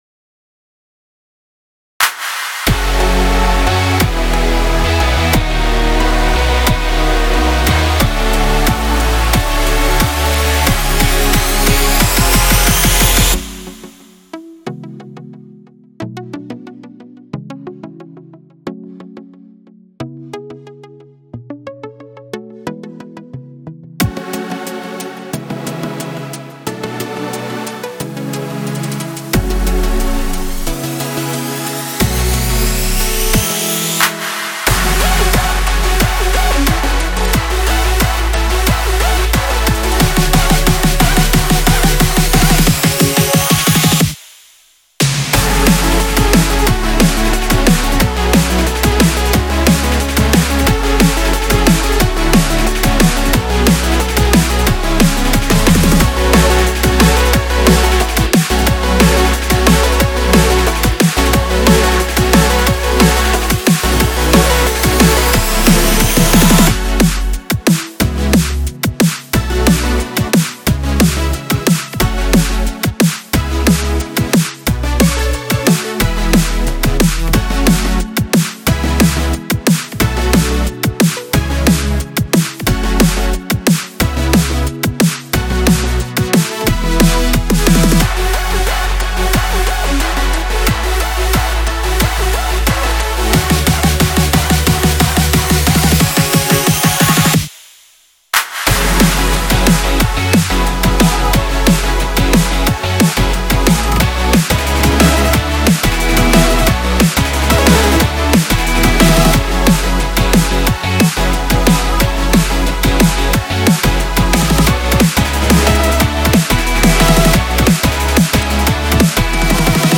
Presentation-Drumnbass-Instrumental.m4a